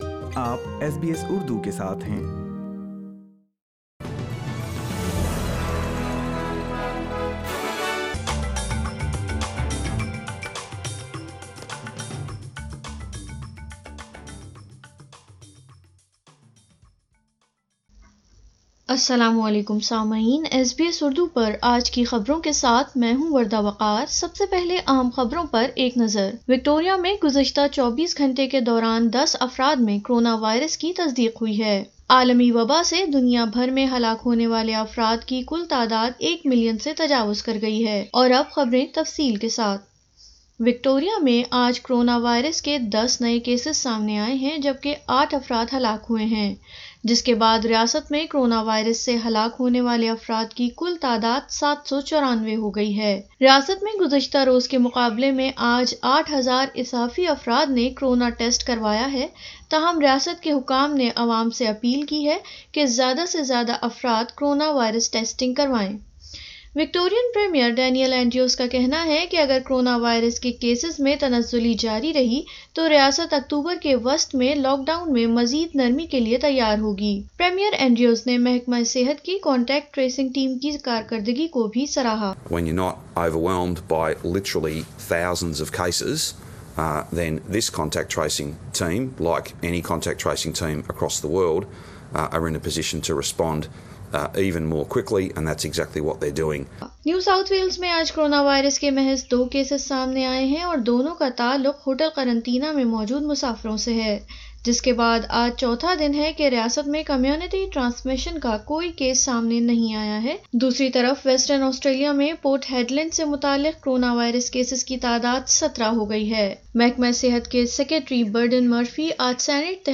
وکٹوریہ میں کرونا وائرس کے دس مزید کیسسز ،اکتوبر کے وسط میںلاک ڈاون میں مزید نرمی کا امکان ۔ سنئیے اردو مین خبریں